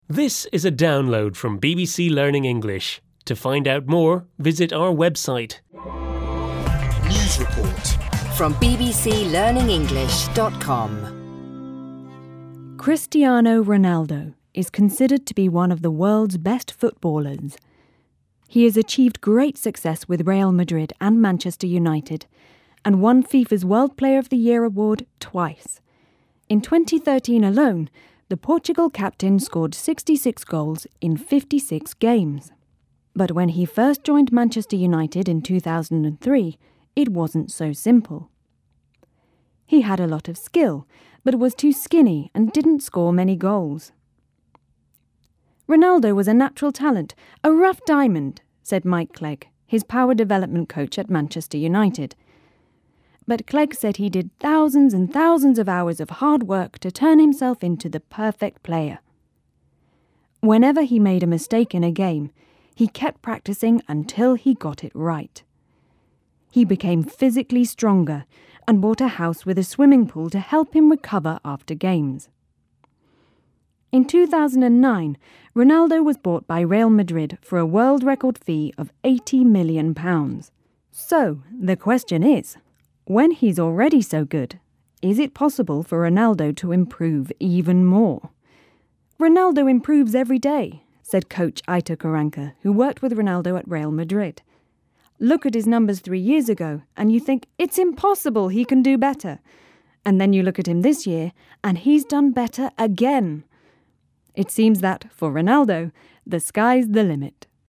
unit-6-3-1-u6_s4_news_report_download.mp3